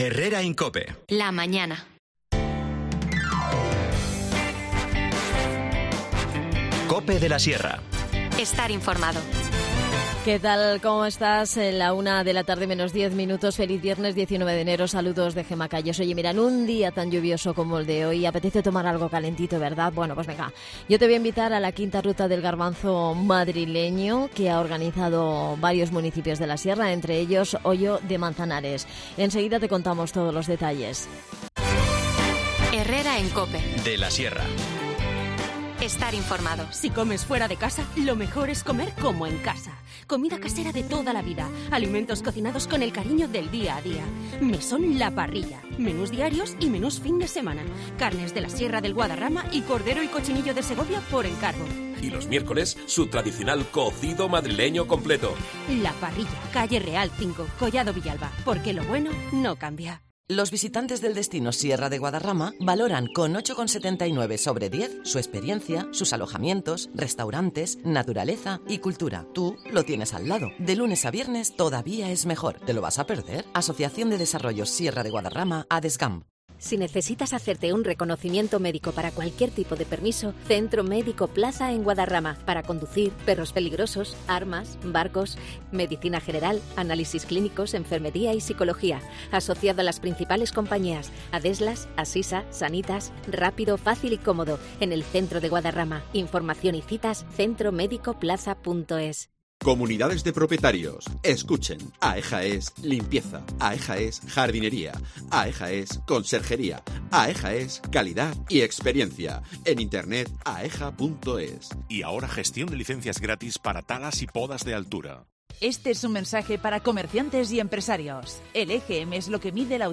Además, hablamos con Victoria Barderas, alcadesa de Hoyo de Manzanares, para conocer cómo participa la localidad en esta ruta y descubrir de paso los muchos atractivos turísticos que brinda el municipio.